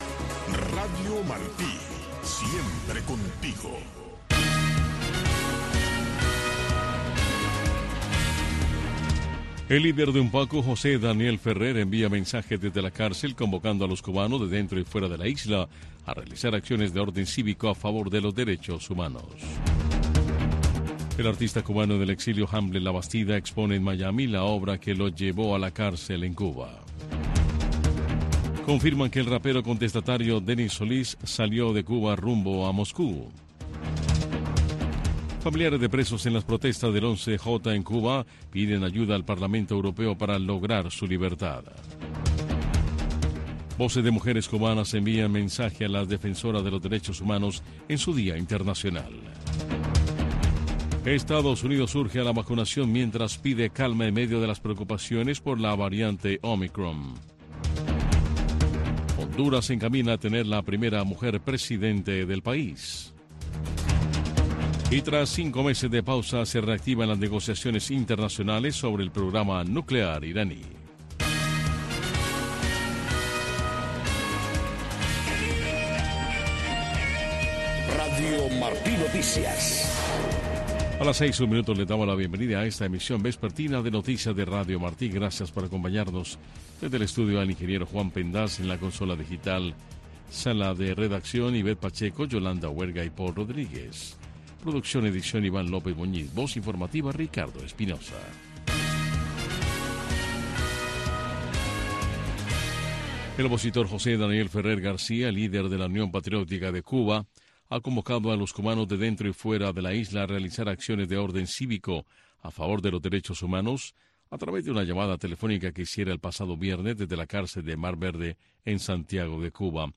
Noticiero de Radio Martí 6:00 PM